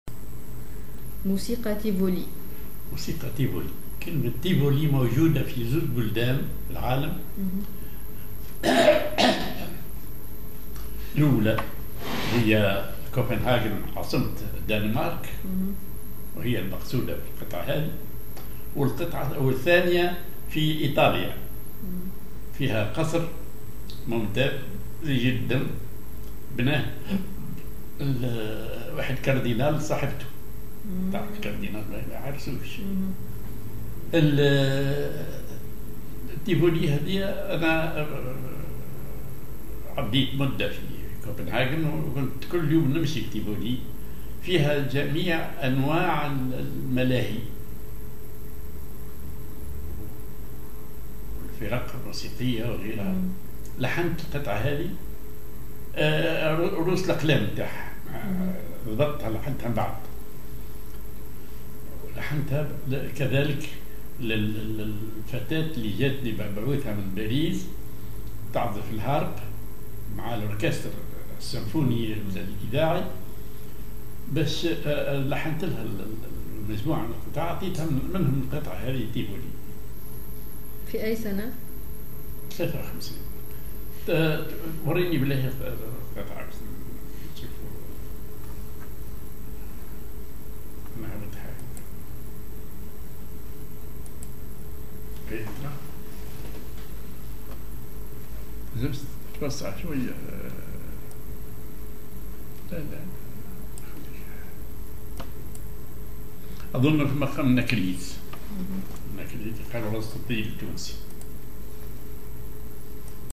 Maqam ar النكريز
معزوفة